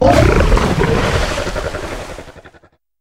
Cri d'Oyacata dans Pokémon HOME.